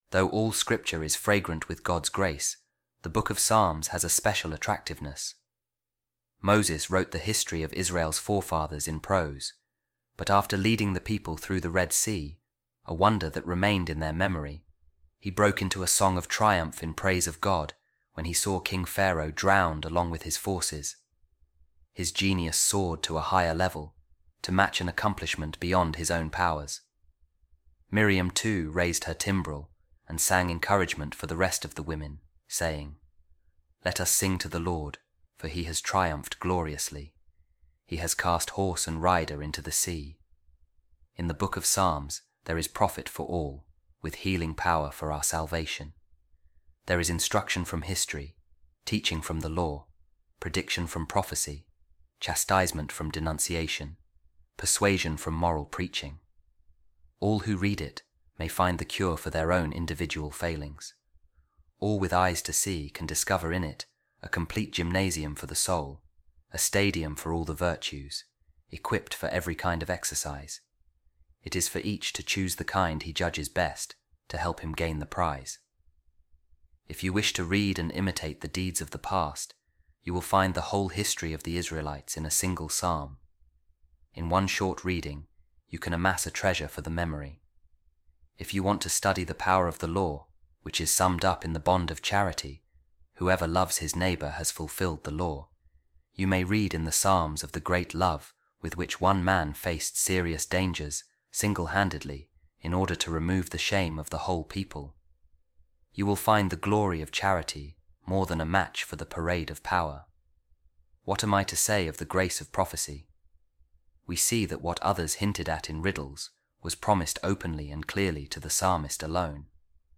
A Reading From The Discourse Of Saint Ambrose On The Psalms | The Delightful Book Of The Psalms